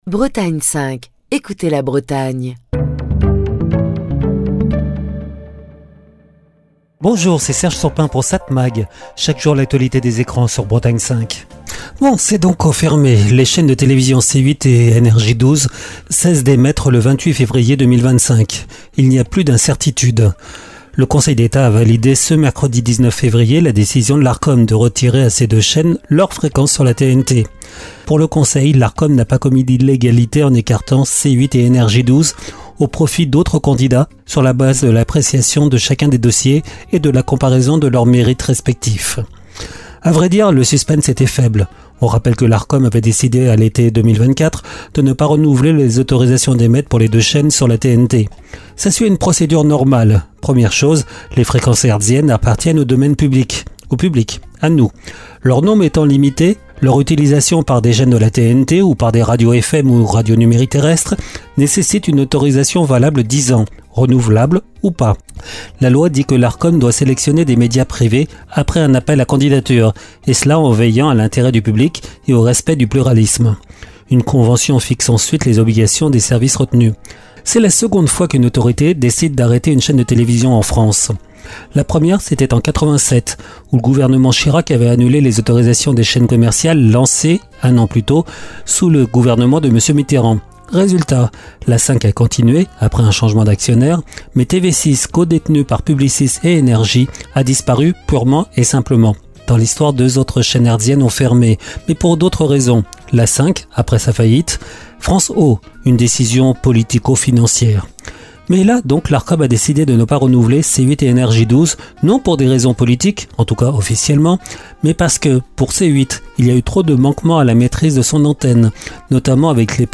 Chronique du 24 février 2025.